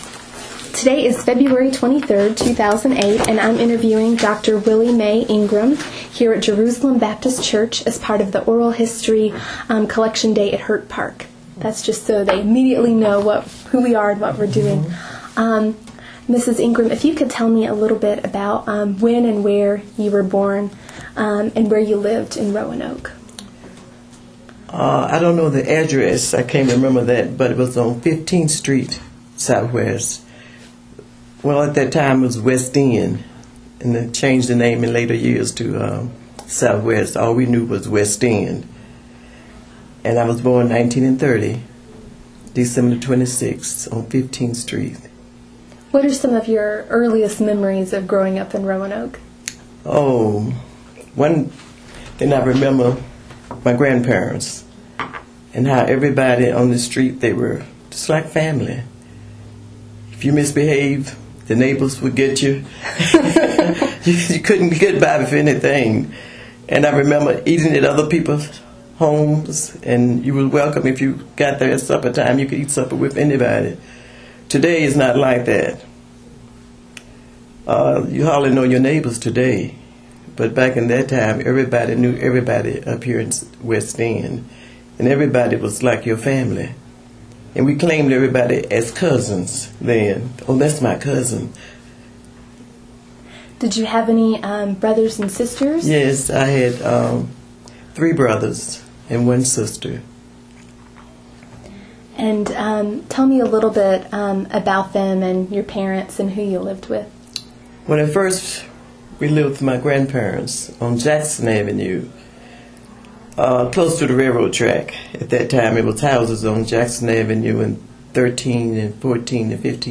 Neighborhood History Interview
Location: Jerusalem Baptist Church